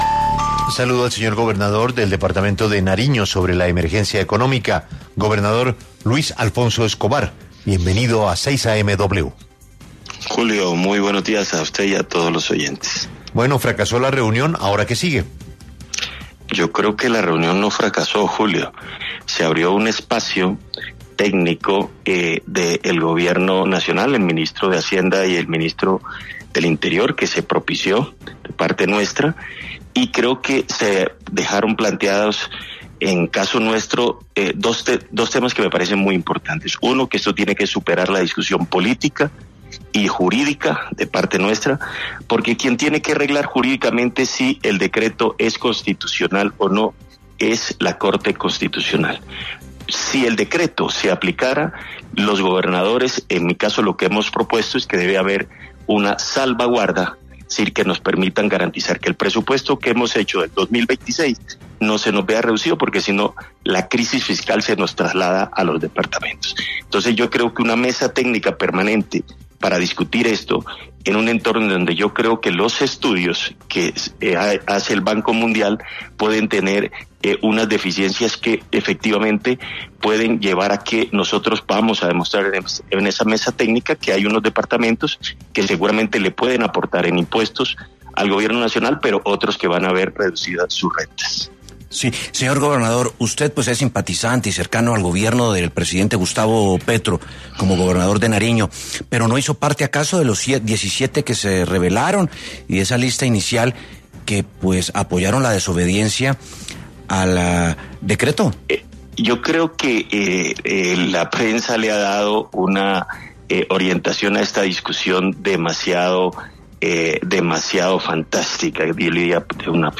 El gobernador de Nariño, Luis Alfonso Escobar, habló en los micrófonos de 6AM W para hablar y entregar detalles de la reunión entre el Gobierno Nacional y gobernadores, en la que expresaron sus inconformidades por posibles efectos del decreto de la emergencia económica.